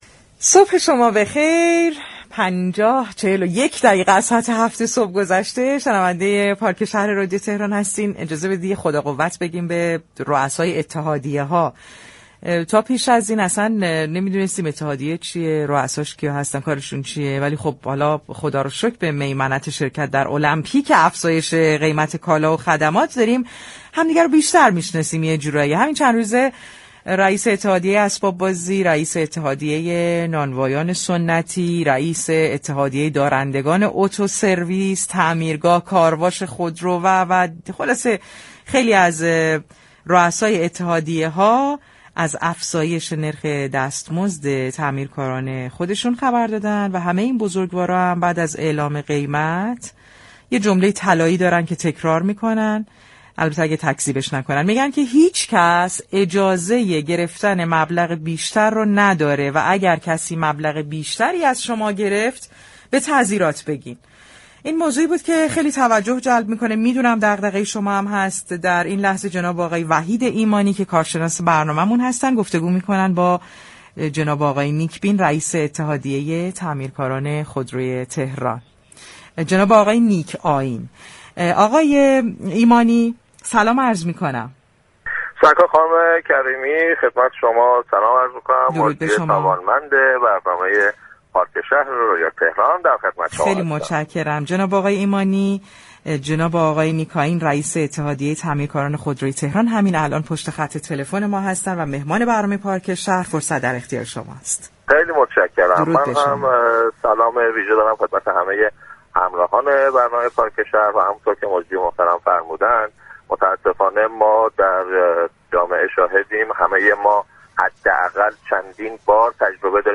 در گفتگو با پارك شهر رادیو تهران